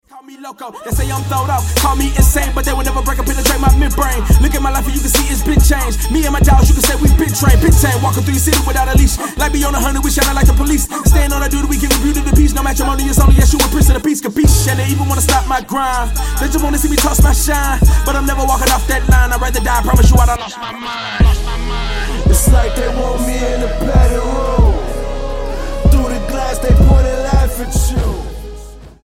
STYLE: Hip-Hop
holding forth over a pulsating southern break